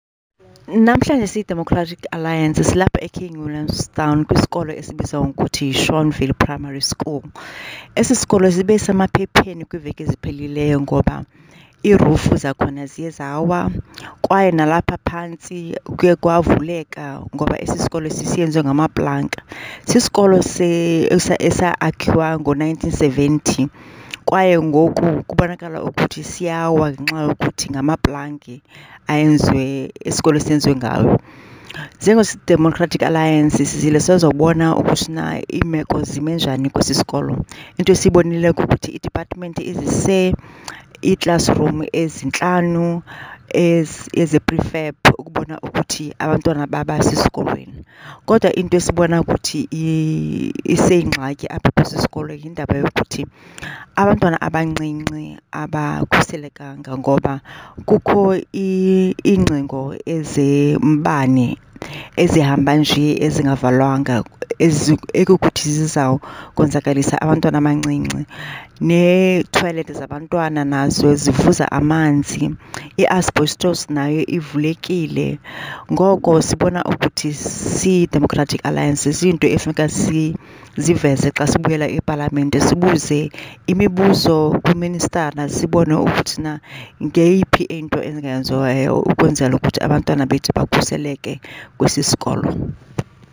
The statement below follows an oversight visit to Schornville Primary School by DA Shadow Deputy Minister of Basic Education, Nomsa Marchesi MP, and DA Shadow MEC for Education in the Eastern Cape, Edmund van Vuuren MPL. Please find attached soundbites by Nomsa Marchesi MP in